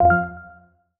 Simple Cute Alert 20.wav